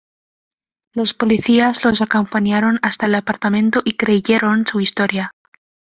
Pronunciado como (IPA)
/isˈtoɾja/